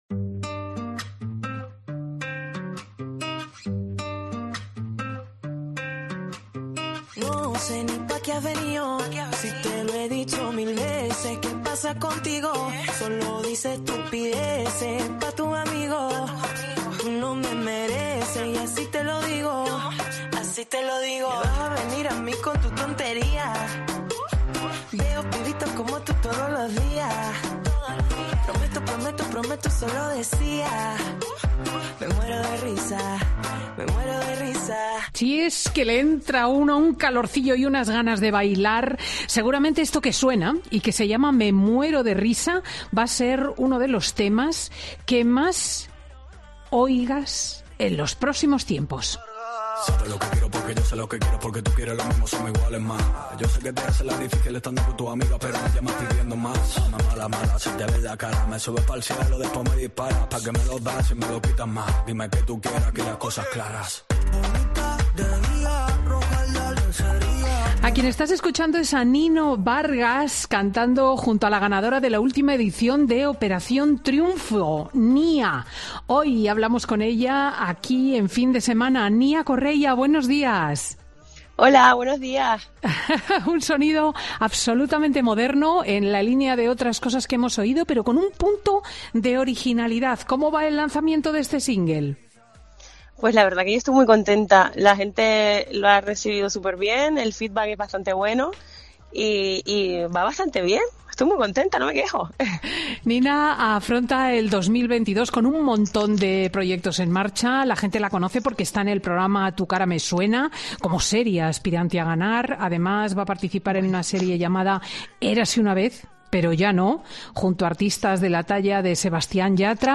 La cantante pasa por Fin de Semana con Cristina para presentar su nuevo sencillo musical y desvelar qué espera de 2022